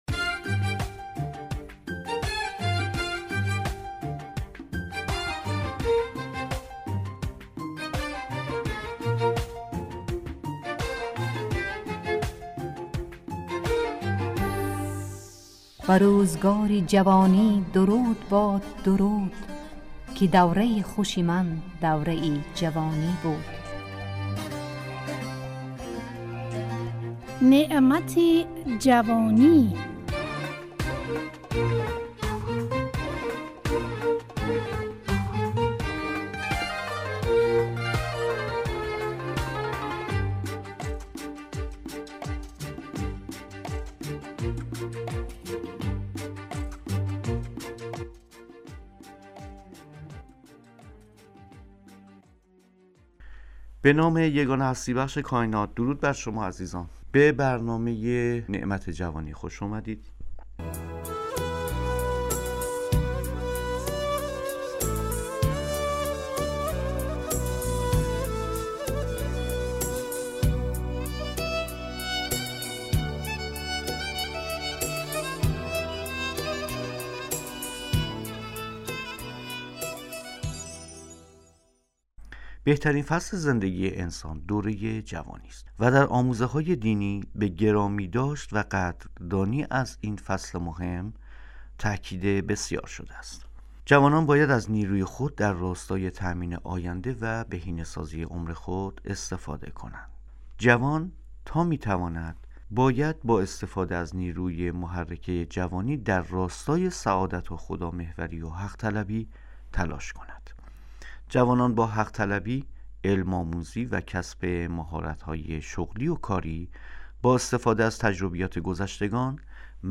نعمت جوانی، برنامه ای از گروه اجتماعی رادیو تاجیکی صدای خراسان است که در آن، اهمیت این دوران باشکوه در زندگی انسان مورد بررسی قرار می گیرد.